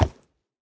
sounds / mob / horse / wood1.ogg
wood1.ogg